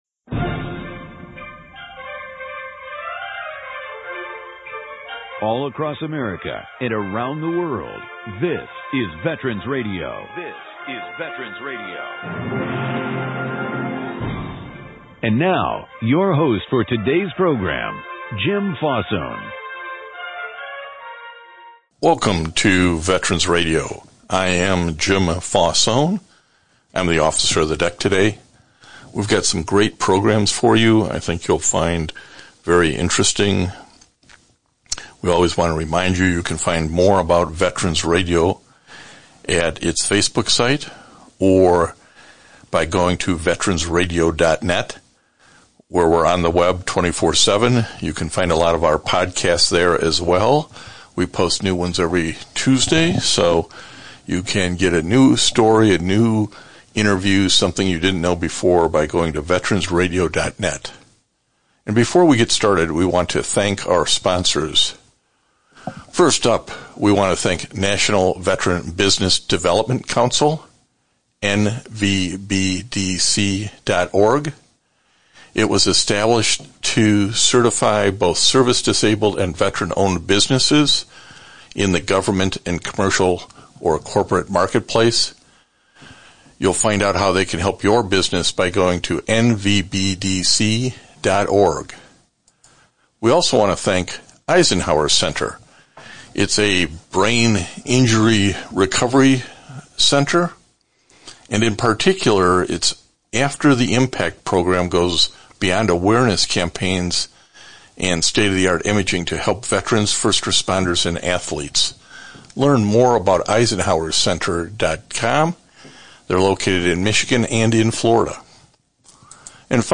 October 11, 2020 This week’s one hour radio broadcast includes two interviews with two women warriors – one of the Army and one of the Navy. Personal stories of service before, during and after military service.